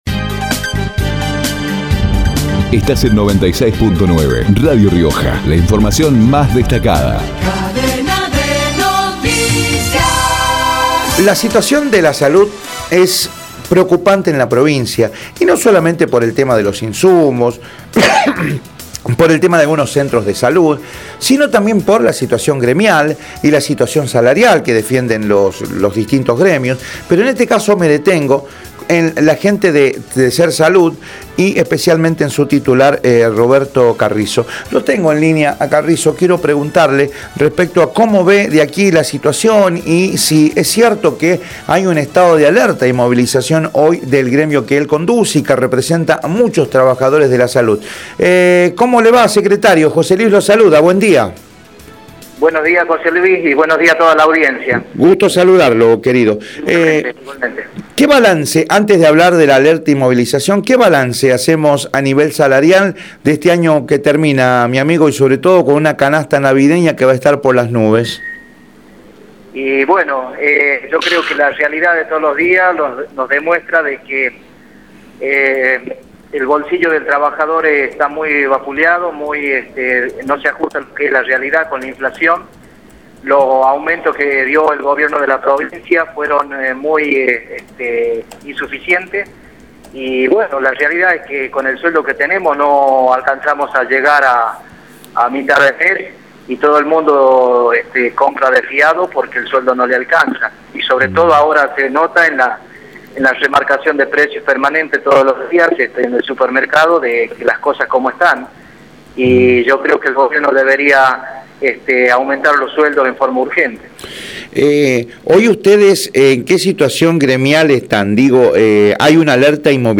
por Radio Rioja